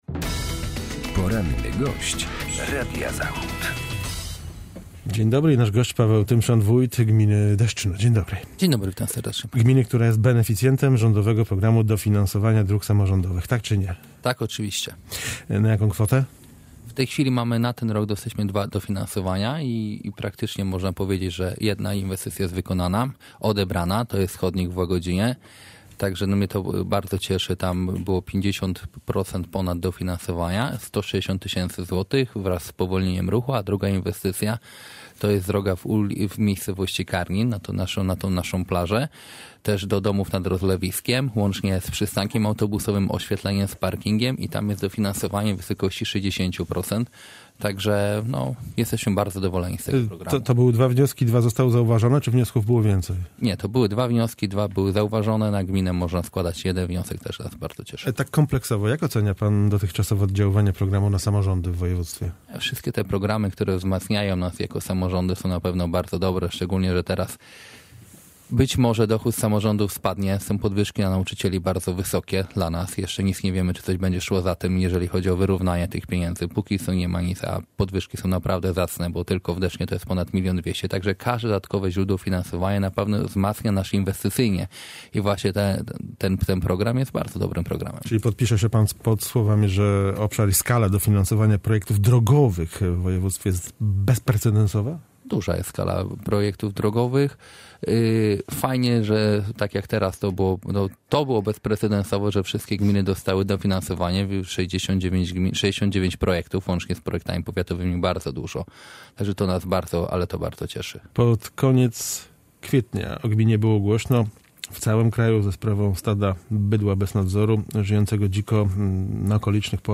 Paweł Tymszan, wójt gminy Deszczno